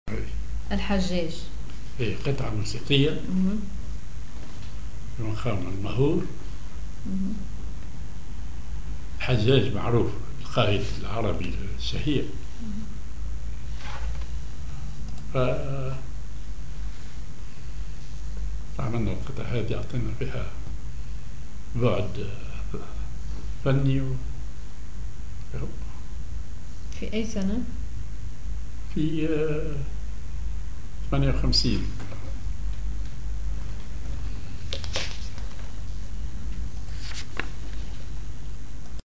Maqam ar ماهور
genre أغنية
معزوفة